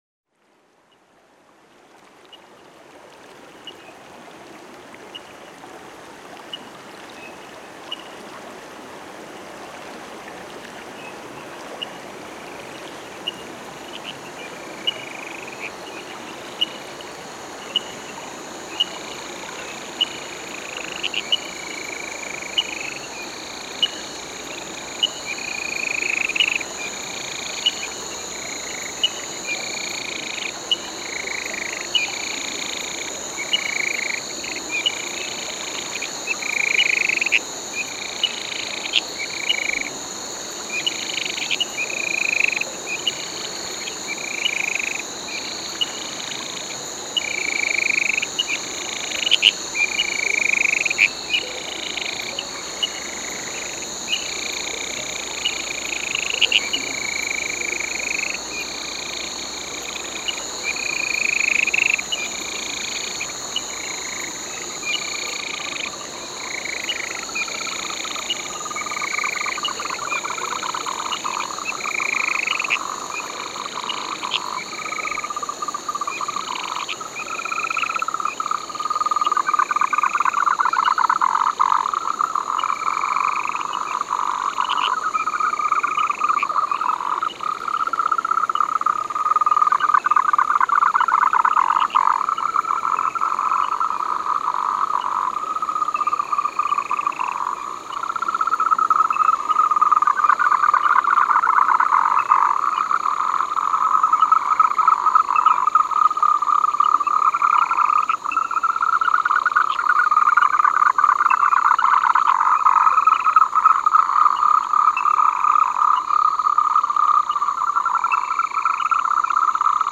（莫氏树蛙、日本树蛙、艾氏树蛙）